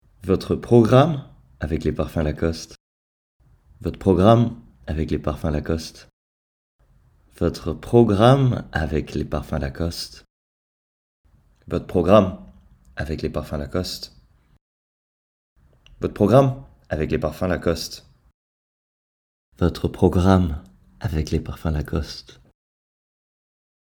Bandes-son
Comédien